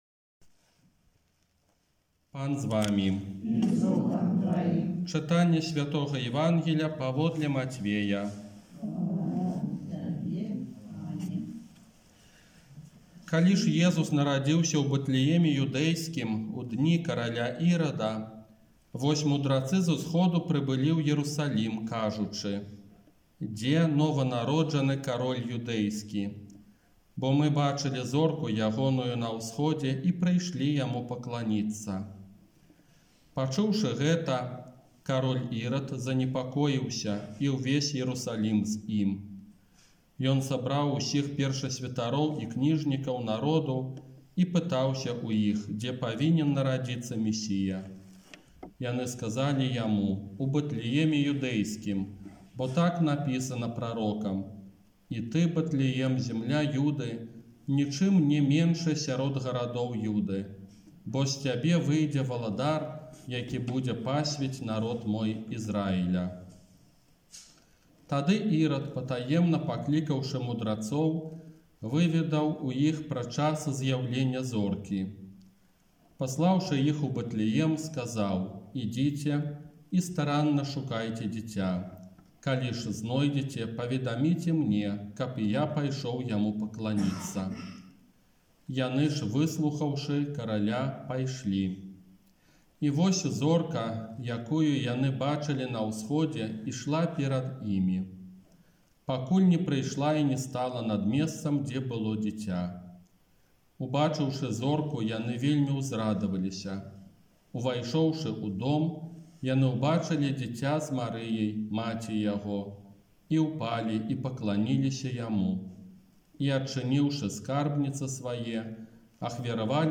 ОРША - ПАРАФІЯ СВЯТОГА ЯЗЭПА
Казанне на Ўрачыстасць Аб'яўлення Пана